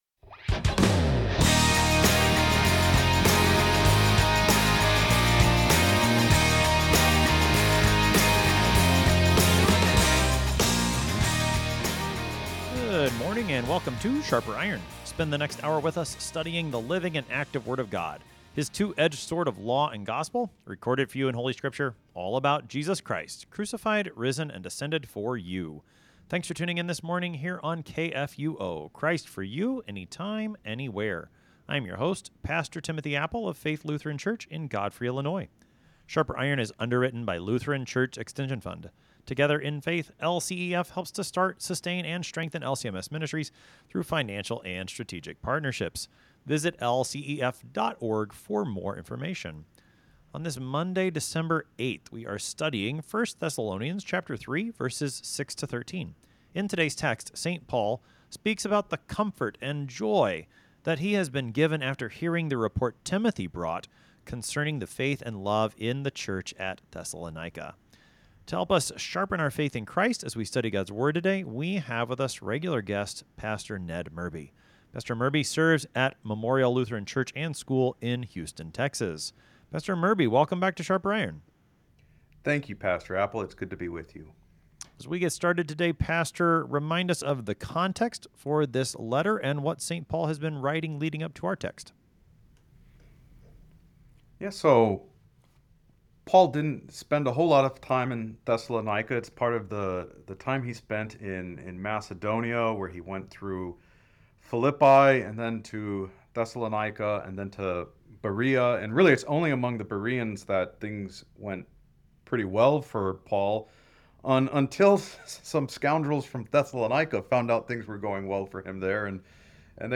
Two pastors engage with God’s Word to sharpen not only their own faith and knowledge, but the faith and knowledge of all who listen.